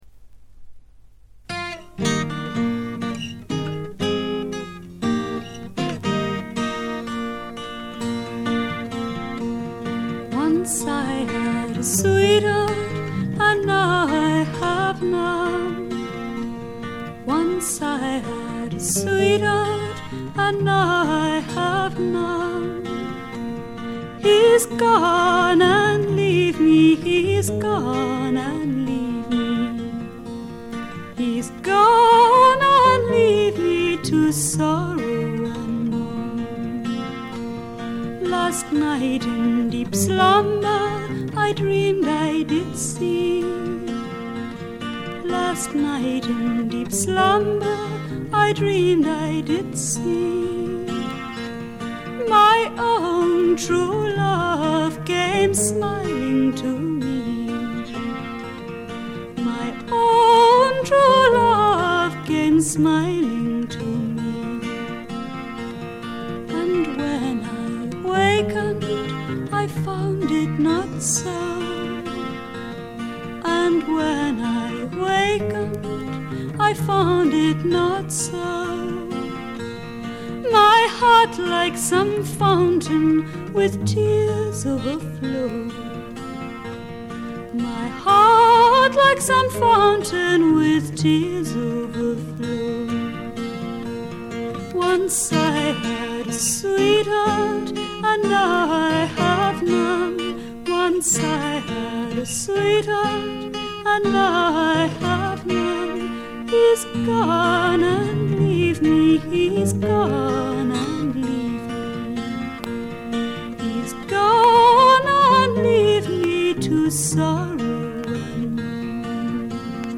全体に軽微なバックグラウンドノイズ。
英国フィメール・フォークの大名作でもあります。
内容はというとほとんどがトラディショナル・ソングで、シンプルなアレンジに乗せた初々しい少女の息遣いがたまらない逸品です。
モノラル盤です。
試聴曲は現品からの取り込み音源です。